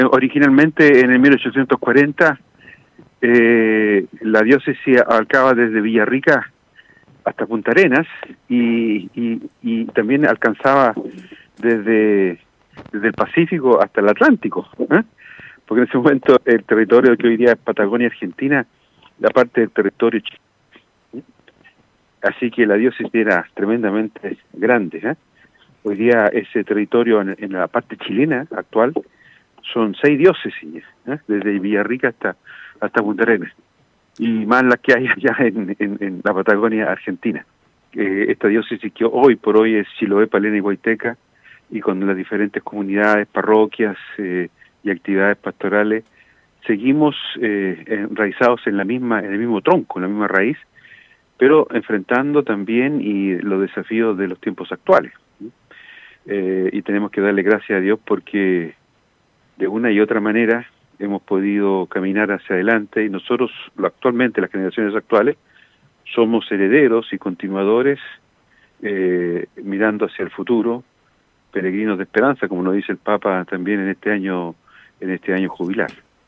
En el marco de esta conmemoración, conversamos en Radio Estrella del Mar, con Monseñor Juan María Agurto, quien comenzó haciendo un repaso por los capítulos iniciales en la historia de esta Diócesis.